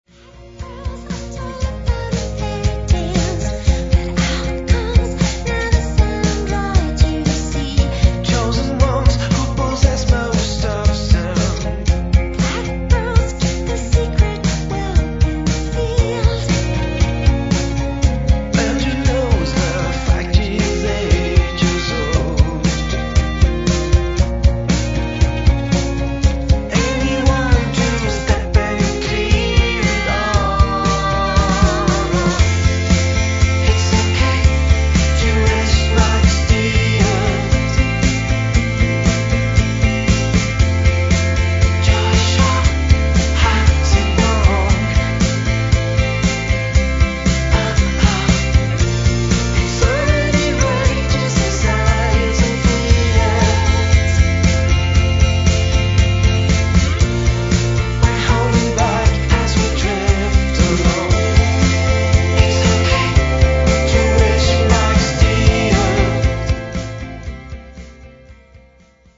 ３年ぶりの2011年作は、レトロでモダンな香り漂うブリティッシュサウンド
vocals, acoustic guitars
vocals, programming, all instruments